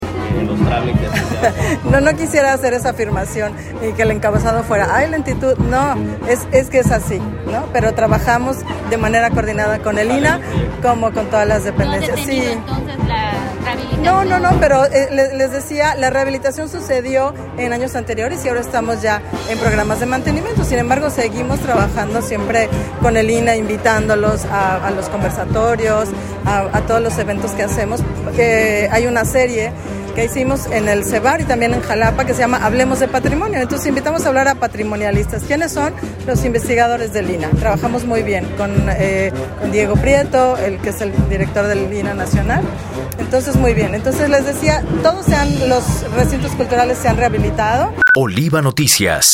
En entrevista, refirió que alrededor de la mitad de los 17 recintos del Instituto Veracruzano de Cultura se encuentran albergados en recintos históricos, por lo que para realizar alguna rehabilitación se necesitan permisos del INAH y presentar los materiales que se utilizarán, sin embargo, no existen problemas.